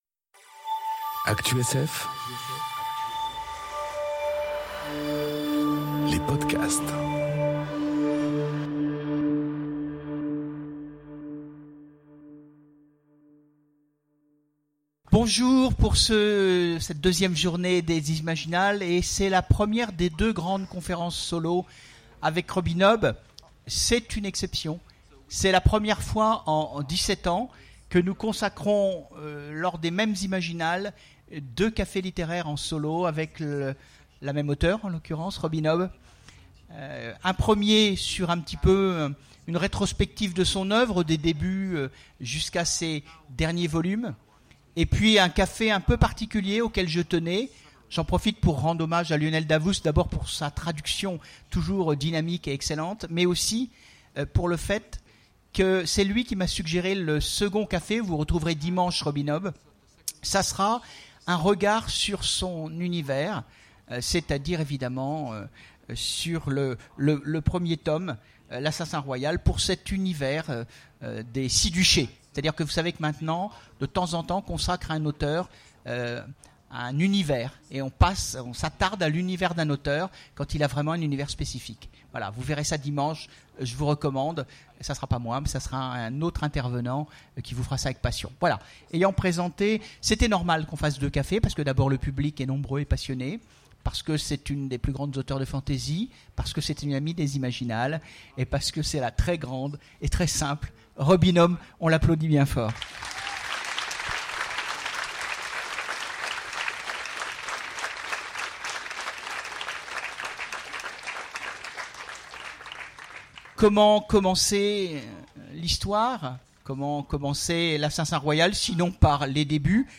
Entretien avec Robin Hobb enregistré aux Imaginales 2018